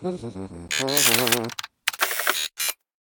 dronereloading1.ogg